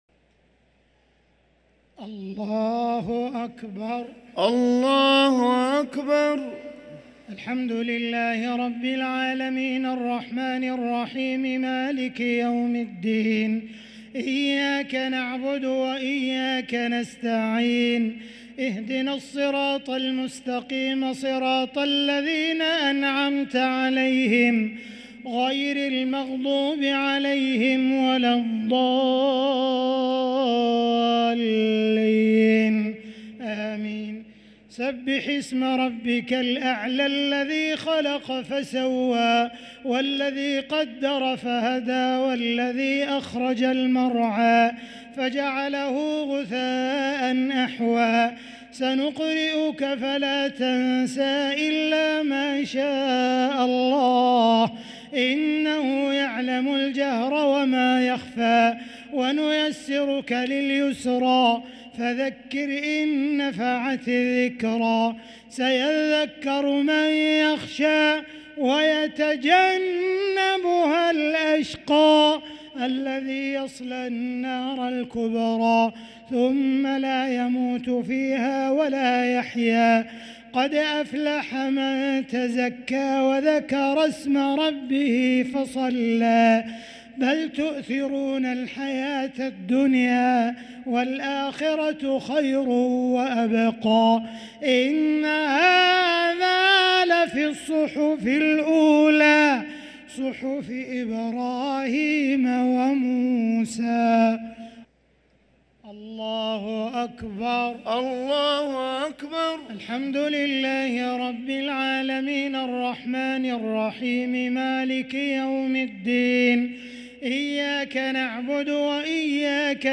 صلاة التراويح ليلة 1 رمضان 1444 للقارئ عبدالرحمن السديس - الشفع والوتر - صلاة التراويح